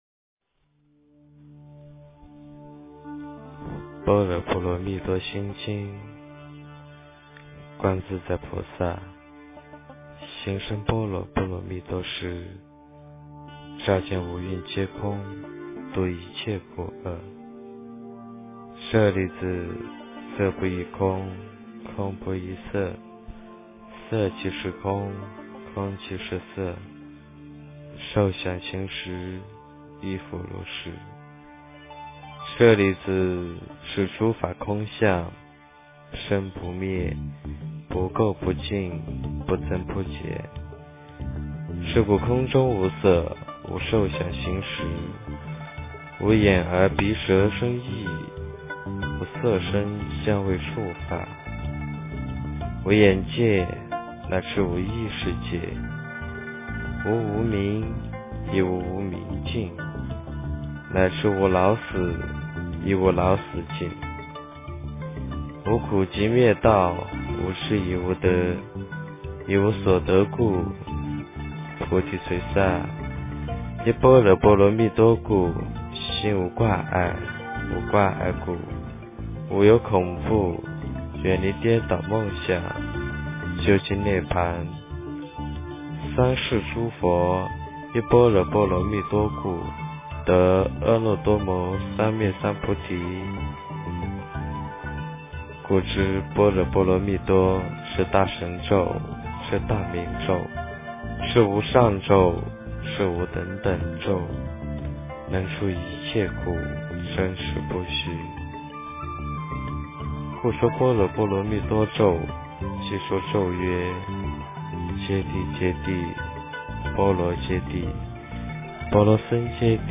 般若波罗蜜多心经 诵经 般若波罗蜜多心经--时部 点我： 标签: 佛音 诵经 佛教音乐 返回列表 上一篇： 佛说长寿灭罪护诸童子陀罗尼经 下一篇： 释迦牟尼佛传30 相关文章 佛陀和盆景--禅定音乐 佛陀和盆景--禅定音乐... 2.成就：波罗蜜--释星云 2.成就：波罗蜜--释星云...